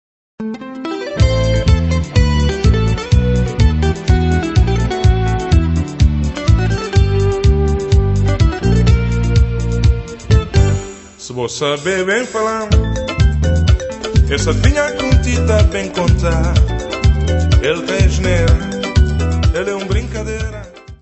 Área:  Tradições Nacionais